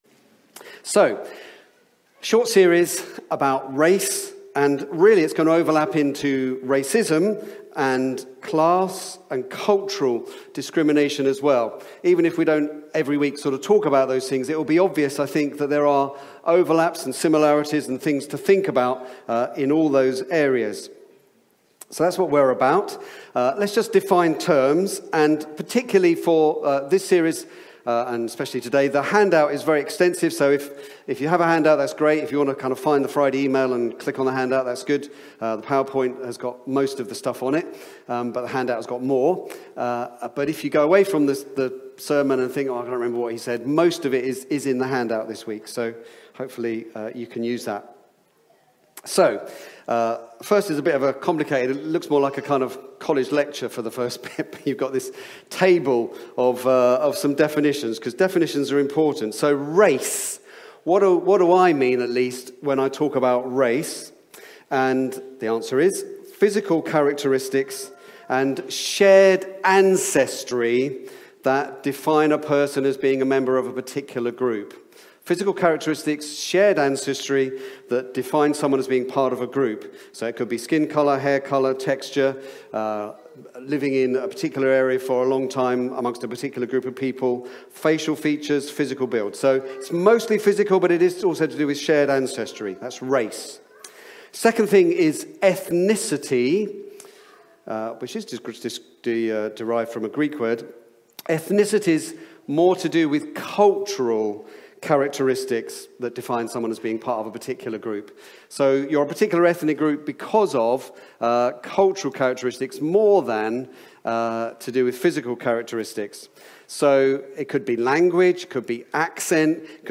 Back to Sermons Race